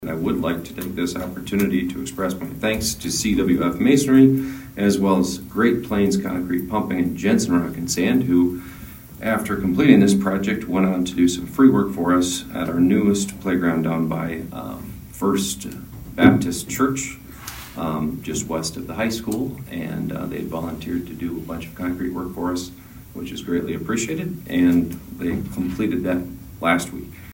ABERDEEN, S.D.(HubCityRadio)- At Monday’s Aberdeen City Council meeting, the council addressed two pay requests for projects currently in the works in Aberdeen.
Mayor Travis Schaunaman took the time to thank those businesses for the playground project next to First Baptist Church on Melgaard for no additional charge.